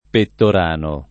Pettorano [ pettor # no ]